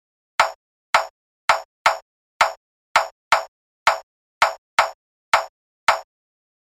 レゲトン(REGGAETON)ドラムの打ち込み
ヒップホップ色が強かったり、レゲエ色が強かったりとかなり幅広いですが、共通の特徴はTresillo（トレシージョ）リズムと呼ばれる、3-3-2で刻むリズムです。
reggaeton0.mp3